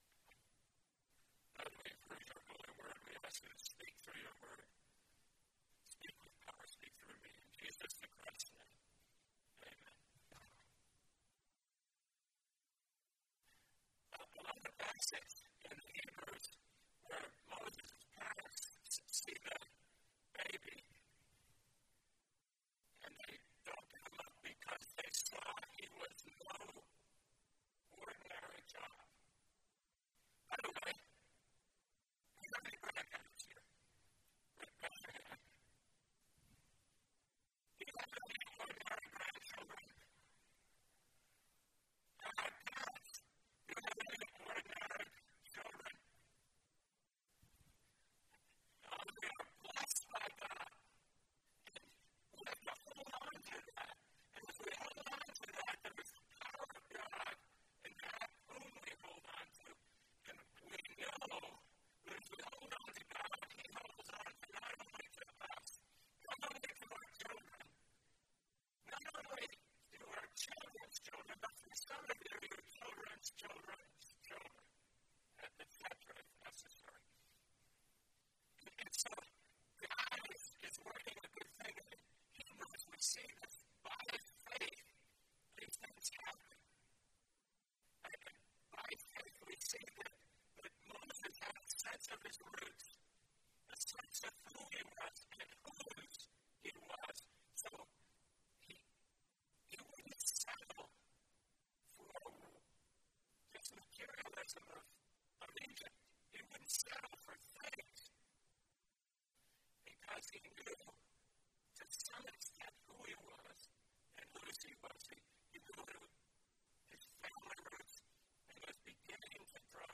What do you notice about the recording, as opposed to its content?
Relationship Prayer - Podcast | The First Presbyterian Church Of Dearborn